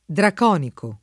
vai all'elenco alfabetico delle voci ingrandisci il carattere 100% rimpicciolisci il carattere stampa invia tramite posta elettronica codividi su Facebook draconico [ drak 0 niko ] o draconitico [ drakon & tiko ] agg.